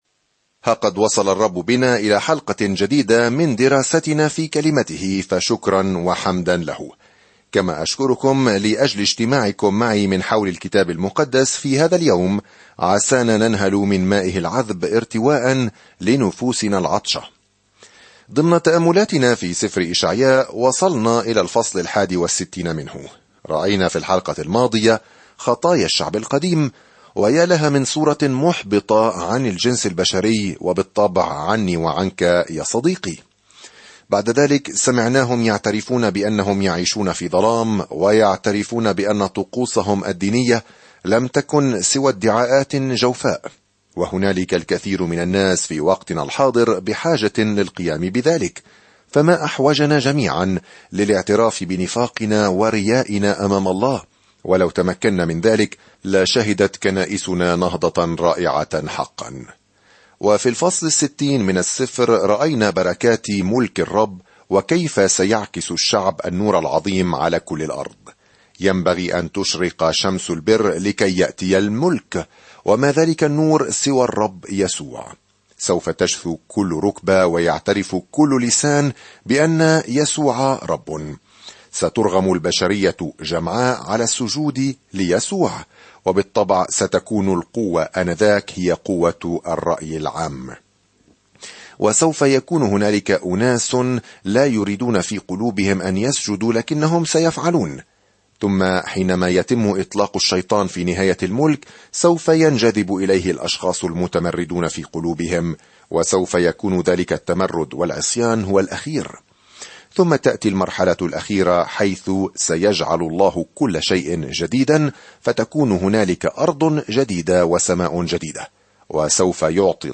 الكلمة إِشَعْيَاءَ 61 إِشَعْيَاءَ 62 يوم 45 ابدأ هذه الخطة يوم 47 عن هذه الخطة ويصف إشعياء، المسمى "الإنجيل الخامس"، ملكًا وخادمًا قادمًا "سيحمل خطايا كثيرين" في وقت مظلم عندما يسيطر الأعداء السياسيون على يهوذا. سافر يوميًا عبر إشعياء وأنت تستمع إلى الدراسة الصوتية وتقرأ آيات مختارة من كلمة الله.